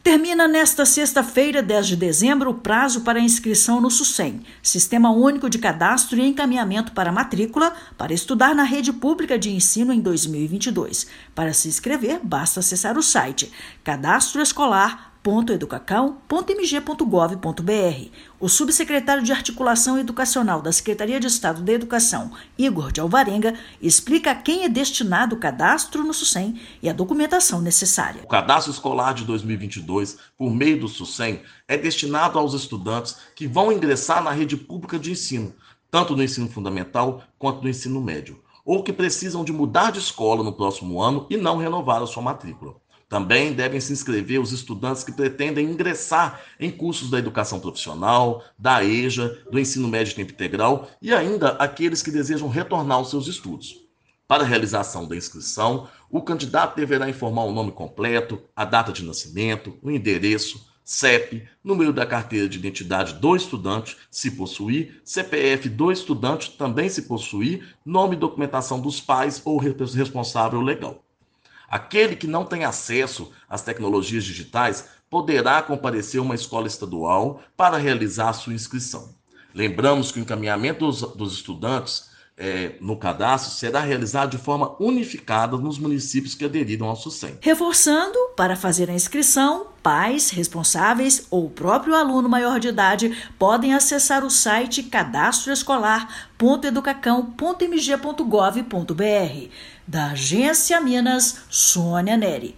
Termina nesta sexta-feira (10/12) o prazo para a inscrição no Sistema Único de Cadastro e Encaminhamento para Matrícula (Sucem) para estudar na rede pública de ensino em 2022. Ouça a matéria de rádio.